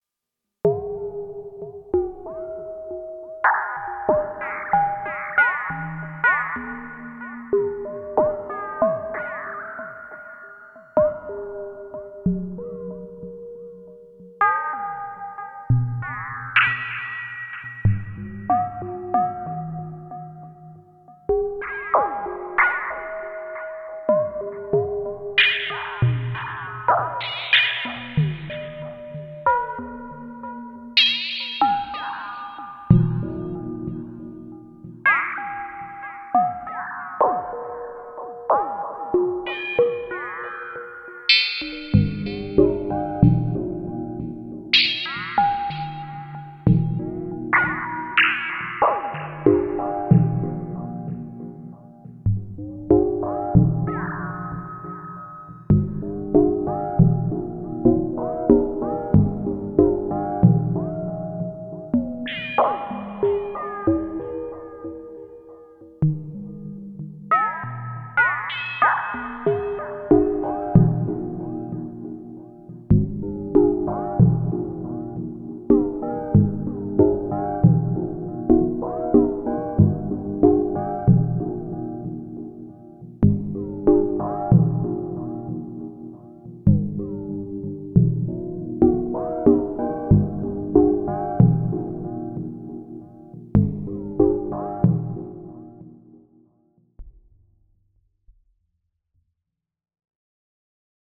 Just a little snippet of the filter 2 used as the sound source and frequency modulated with the lfo. Not the best example of flawless tracking or much of a shot at a piano sound but still something I’m very pleased with as I haven’t really done stuff like this with the A4 before, amazingly enough.